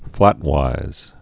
(flătwīz) also flat·ways (-wāz)